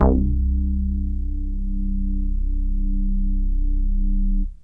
SYNTH BASS-2 0012.wav